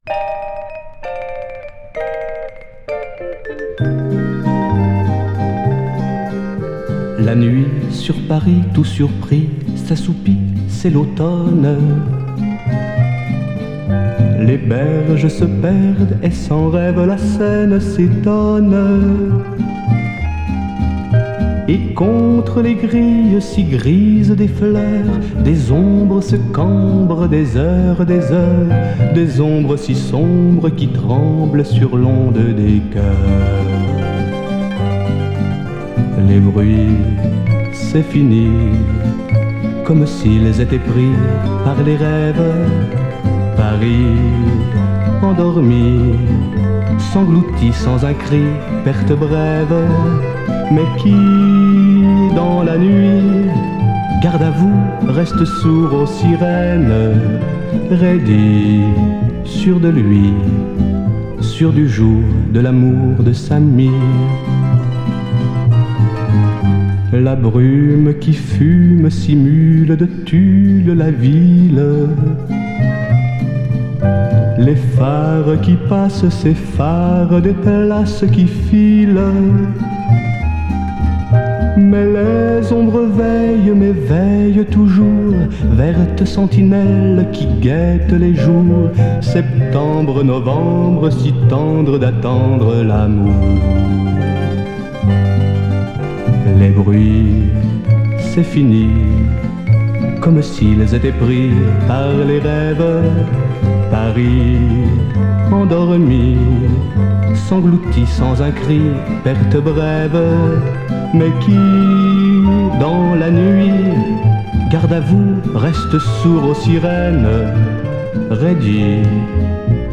Там он перепел советские песенки того времени (русск-франц)